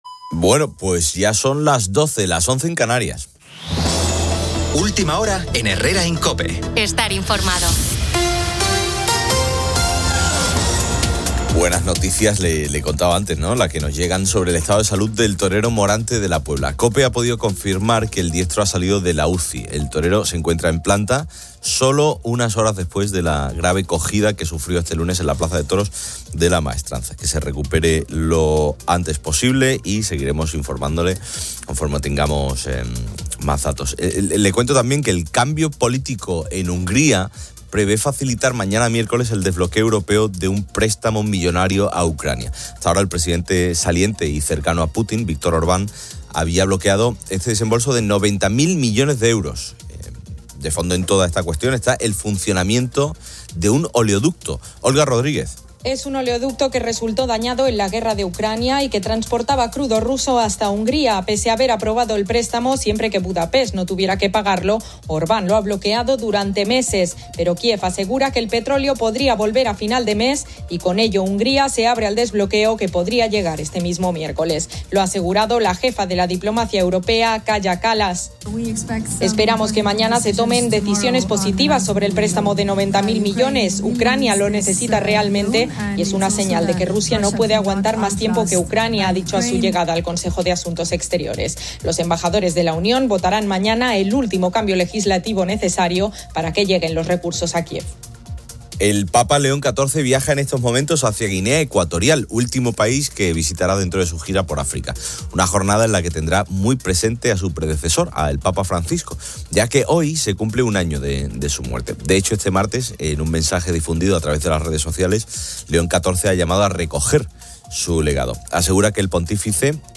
El Papa León XIV finaliza su gira africana en Guinea Ecuatorial, donde se celebra el legado de Francisco y se destaca la fuerte presencia del español. Una entrevista